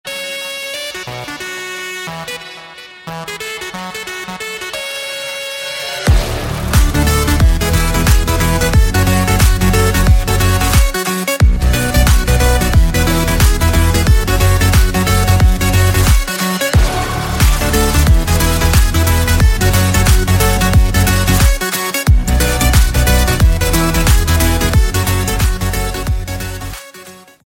Рингтоны Электроника